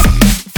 dnbtingsnare(needs cleaning).mp3